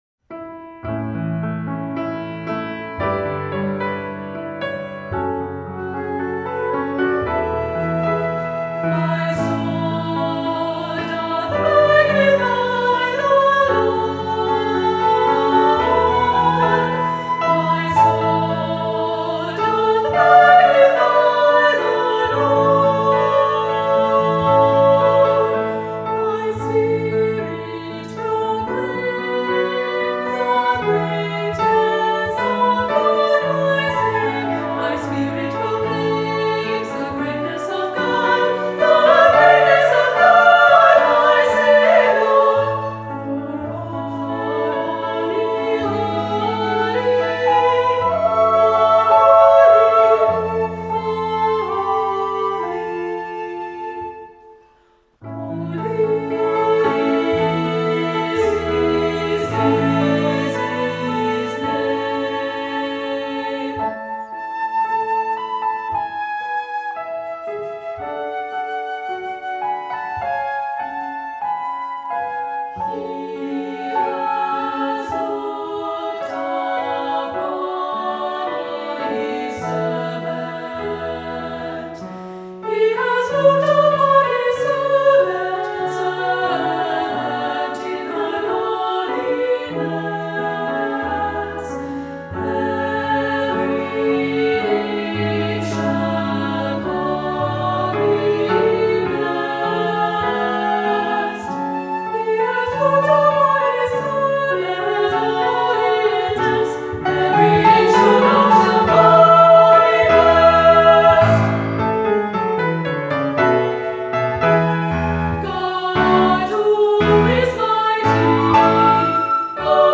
Voicing: Two-part children's choir or two soloists